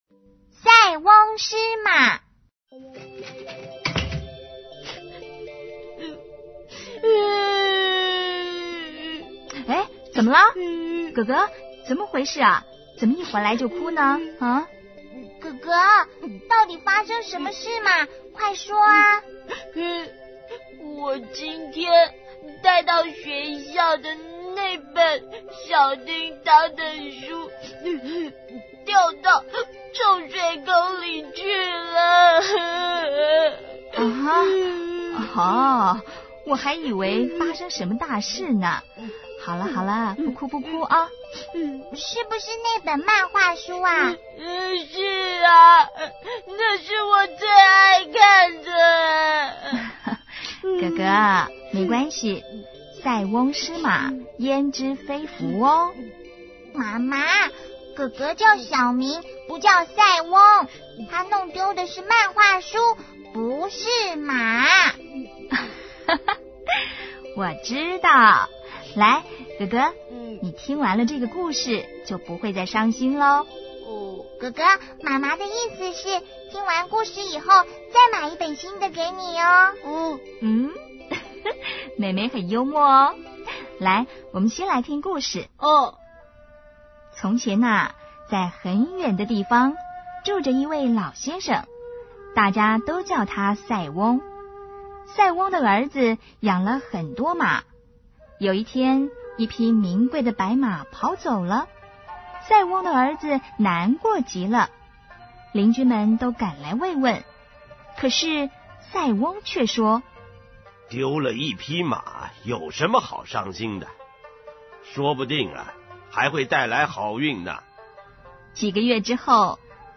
CD 每則成語均錄製成好聽生動的「廣播劇」，增加學習效果。